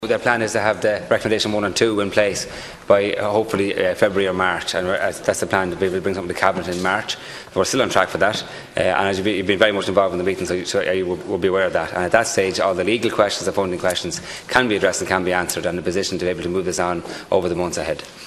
Minister Damien English was quizzed in the Dail this week by Donegal Deputy Charlie Mc Conalogue who requested an update on the matter.
Minister English says all outstanding questions can be answered by March: